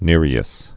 (nîrē-ĭs)